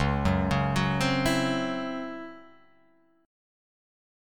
C#mM7bb5 Chord